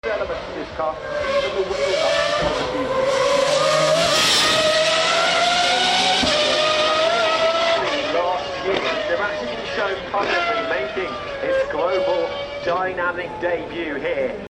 Screaming Pagani Huayra R EVO sound effects free download
Screaming Pagani Huayra R EVO Roadster!